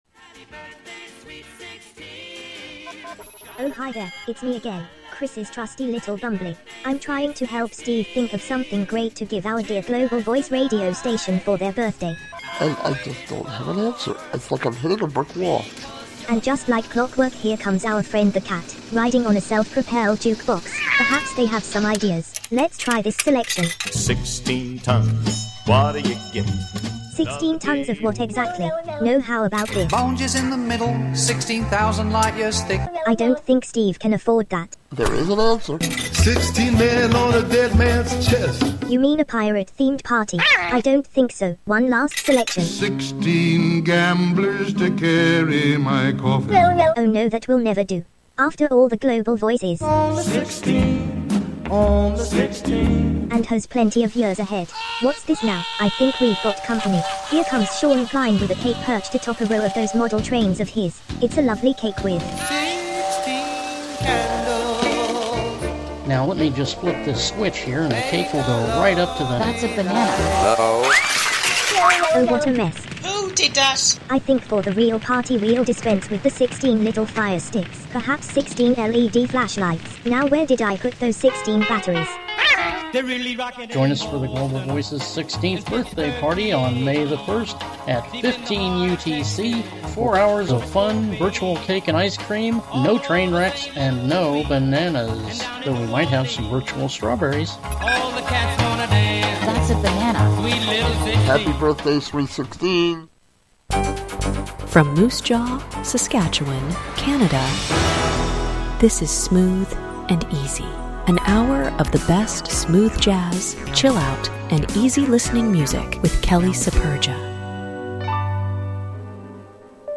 featuring an hour of Smooth Jazz, Chillout and Easy Listening music.
Genres : Easy Listening , Jazz